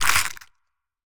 creaking_heart_spawnmob.ogg